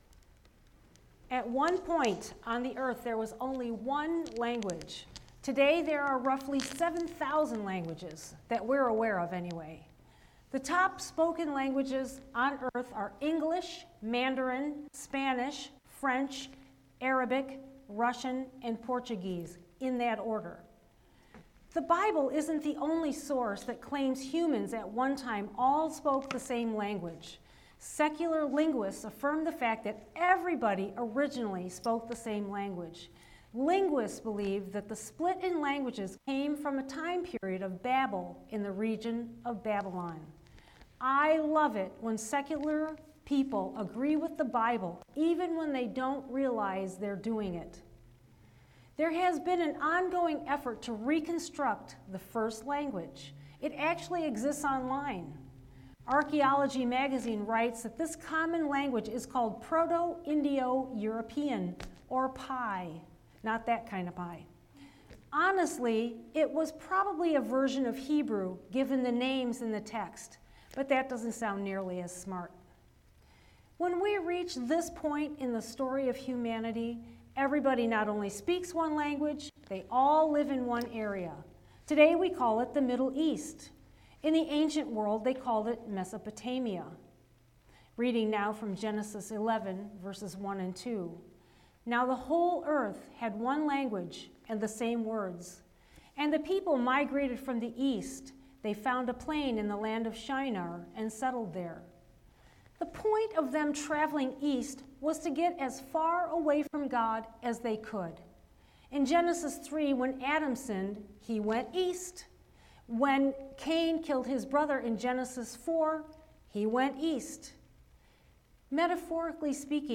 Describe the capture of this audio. A Prayer for Rockford – Faith Wesleyan Church